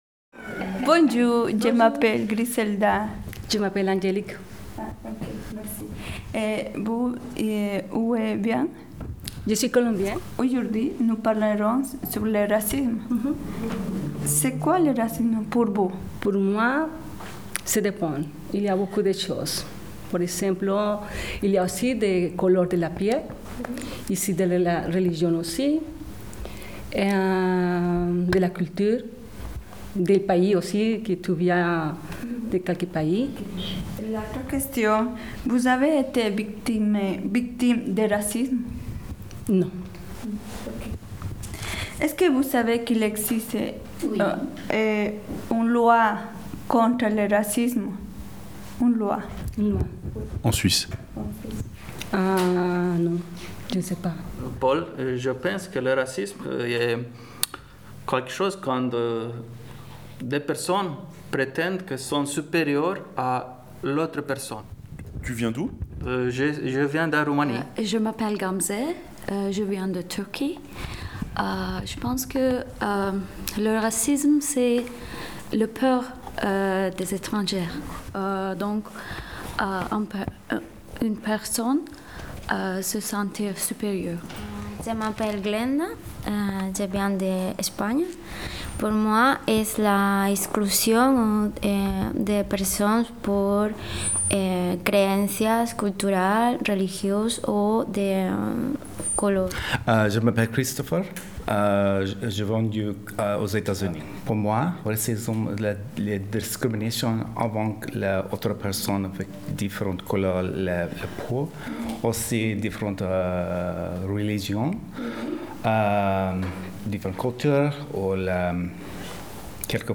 Interviewe menées dans une classe de français de Pôle Sud
Podcast-4_Interview-en-classe.mp3